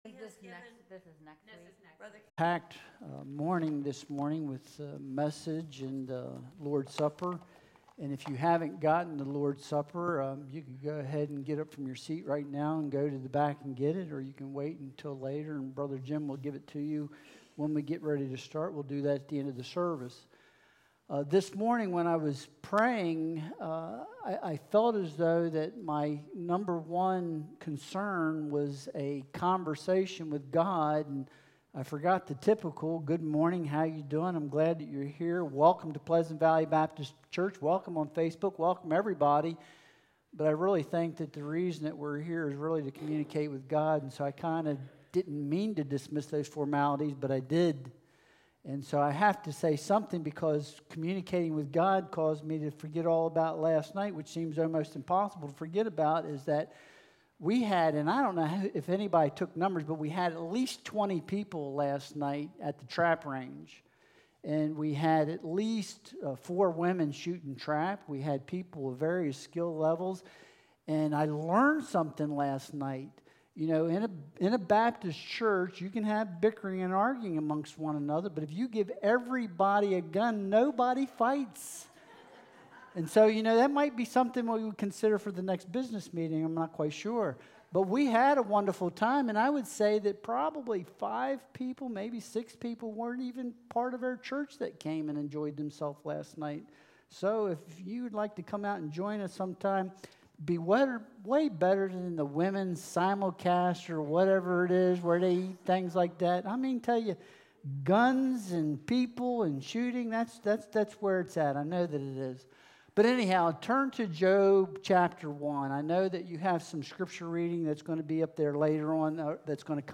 Job 38.1-3 Service Type: Sunday Worship Service « A Goal Without a Plan Is a Pipe Dream Can I Trust This Book?